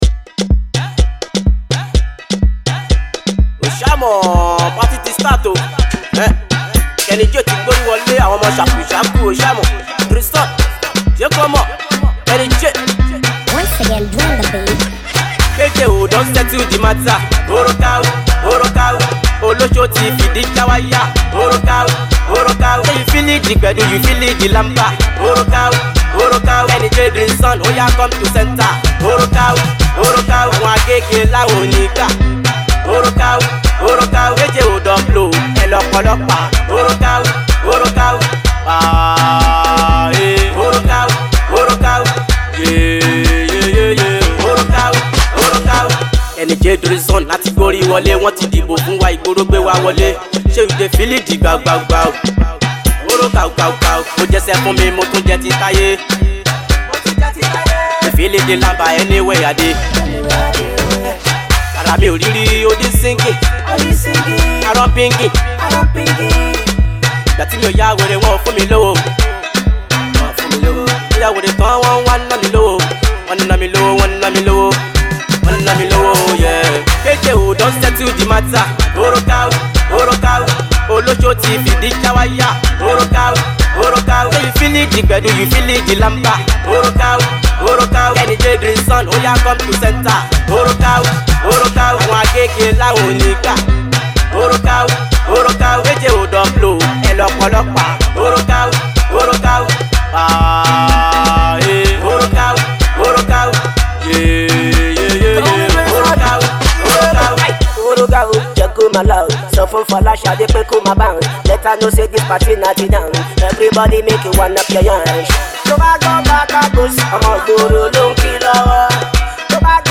dance hall song